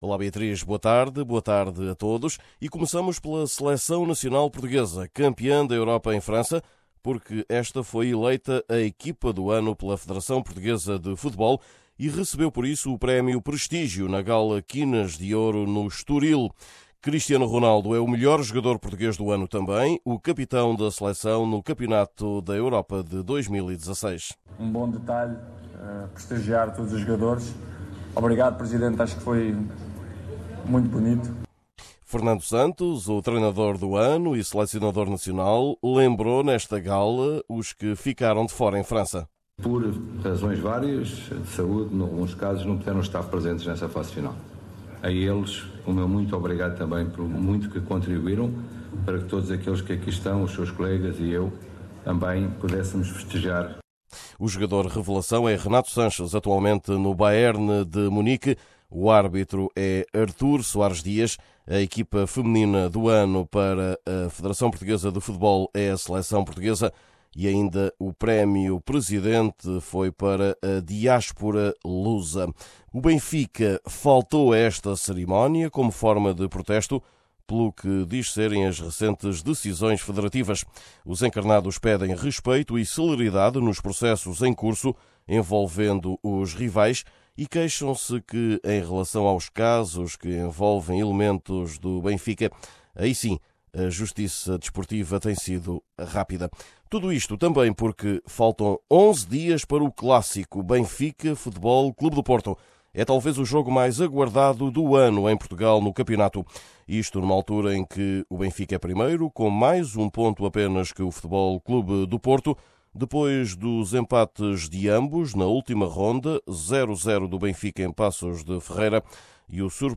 Neste boletim, saiba qual o ambiente em vésperas do muito aguardado clássico que pode decidir o campeonato: Benfica-FC Porto.